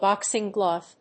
アクセントbóxing glòve